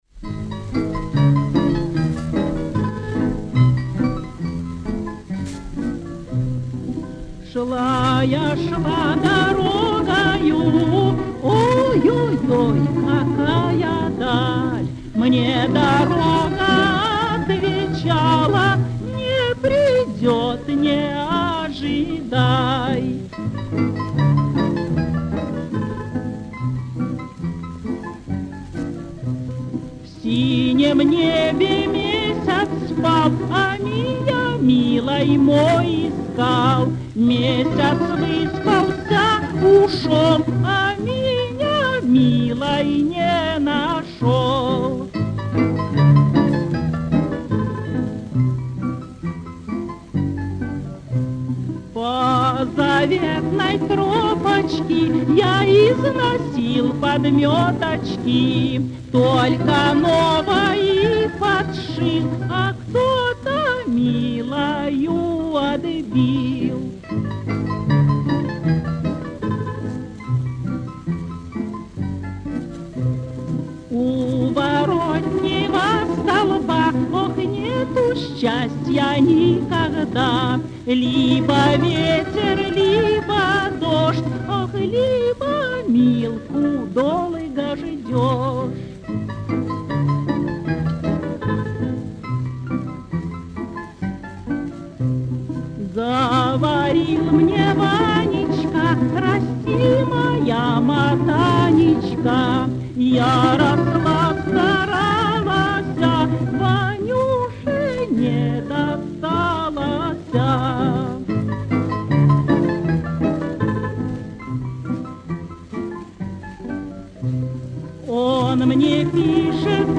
Слушайте частушки!
Исполнение 1939г. Матрица 8900 (патефонная)